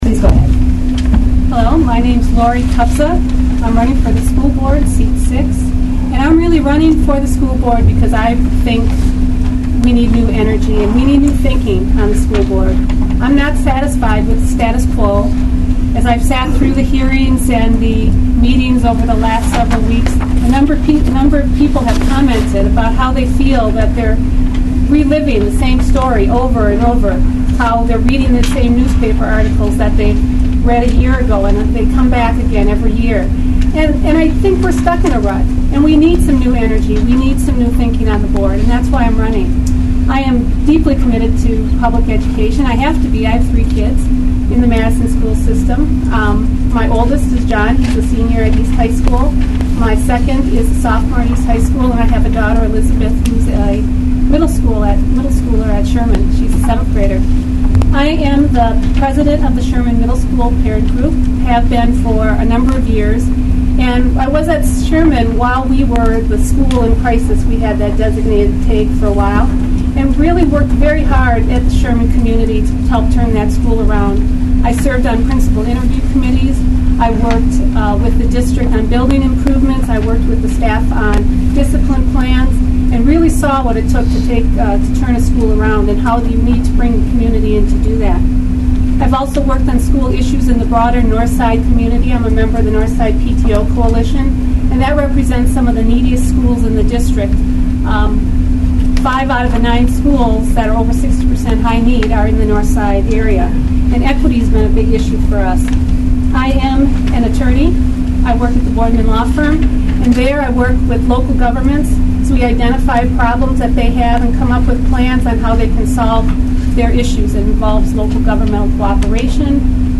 Cherokee School Board Candidate Forum Video/Audio
Several westside PTO's hosted a candidate forum Wednesday evening. The candidates discussed a wide variety of questions, including referendums, the budget process, strings, local education media coverage and differences with their opponents.